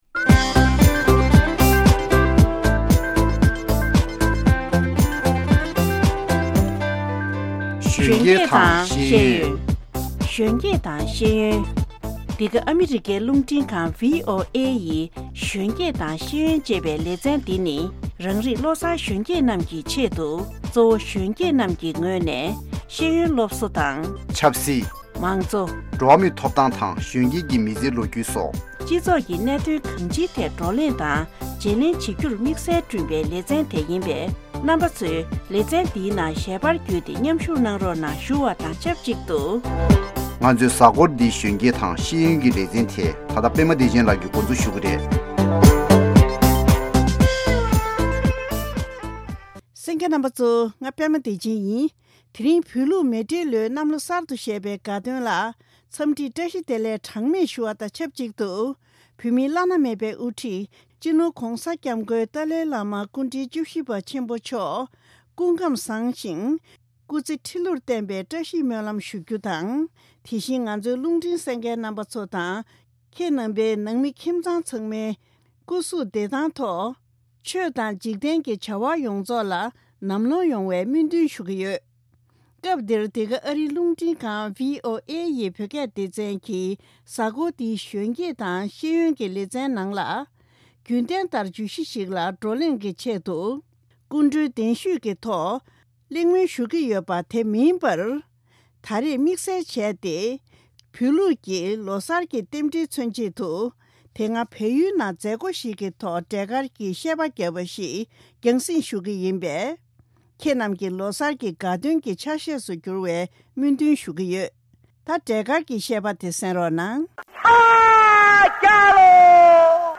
བོད་ལུགས་མེ་སྤྲེལ་ལོའི་གནམ་ལོ་གསར་དུ་བཞད་པའི་དགའ་སྟོན་རྟེན་འབྲེལ་ལ་དེ་སྔ་བལ་ཡུལ་ནང་འཁྲབ་སྟོན་བྱས་པའི་འབྲས་དཀར་གྱི་བཤད་པ་ཞིག་གསན་གནང་གི་རེད།